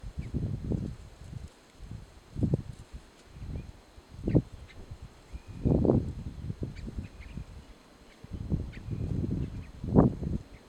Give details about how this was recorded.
Province / Department: Catamarca Location or protected area: Santa María Condition: Wild Certainty: Recorded vocal